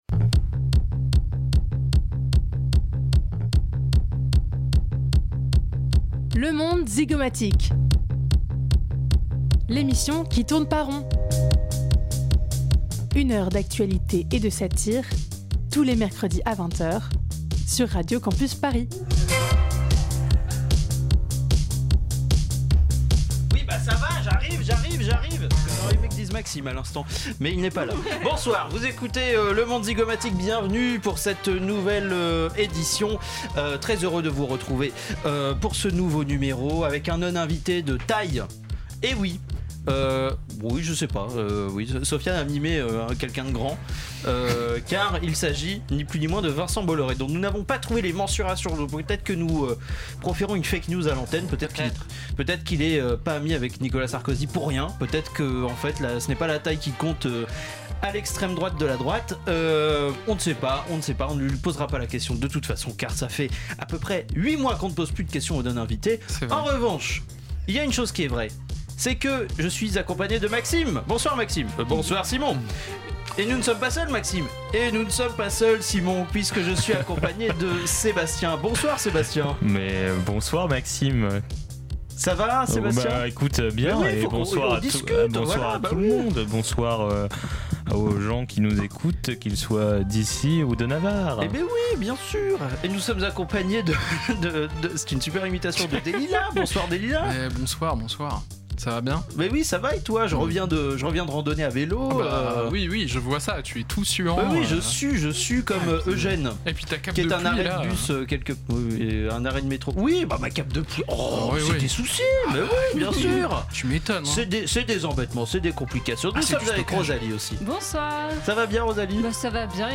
Type Magazine Société